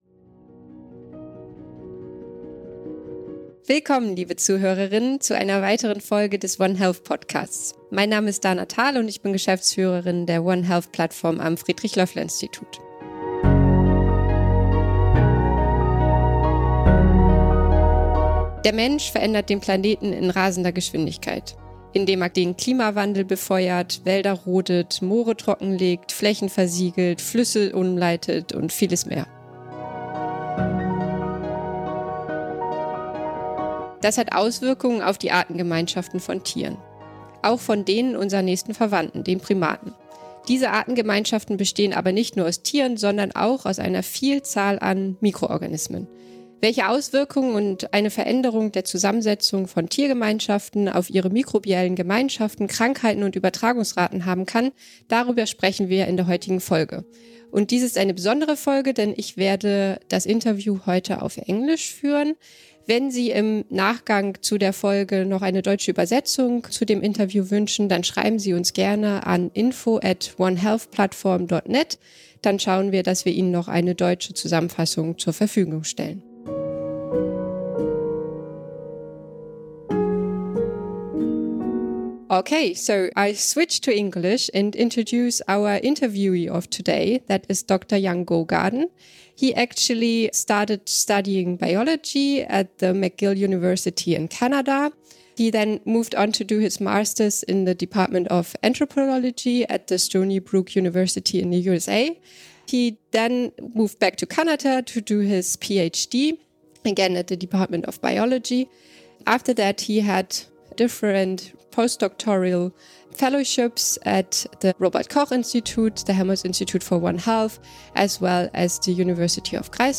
Anmerkung: Das Interview ist auf Englisch.